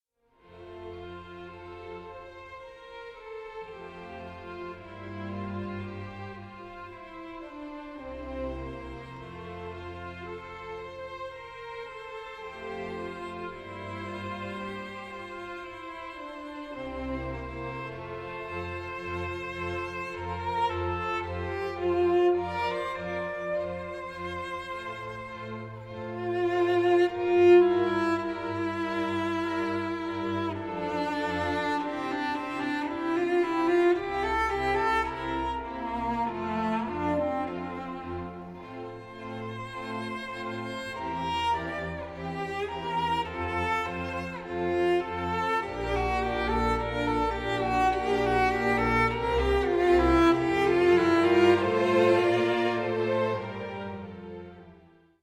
24 bit digital recording
cello